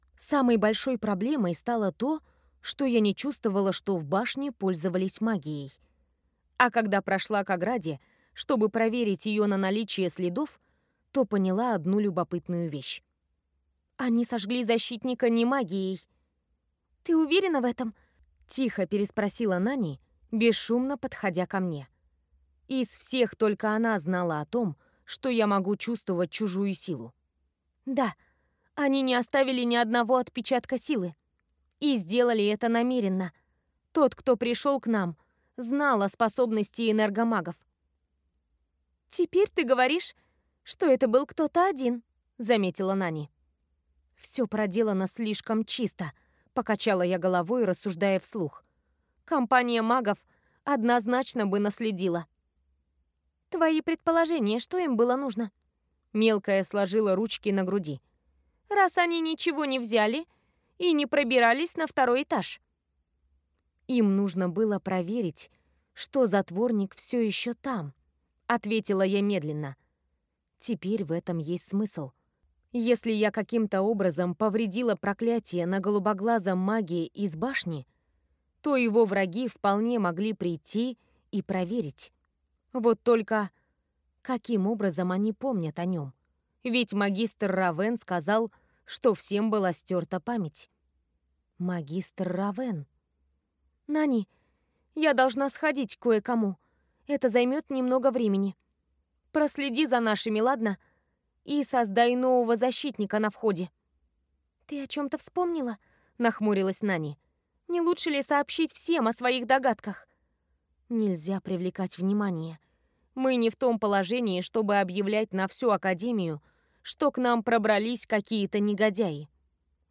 Аудиокнига Изгои академии Даркстоун | Библиотека аудиокниг
Прослушать и бесплатно скачать фрагмент аудиокниги